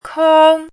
chinese-voice - 汉字语音库
kong1.mp3